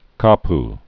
(käp) Hawaii